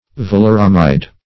Search Result for " valeramide" : The Collaborative International Dictionary of English v.0.48: Valeramide \Val`er*am"ide\, n. [Valeric + amide.]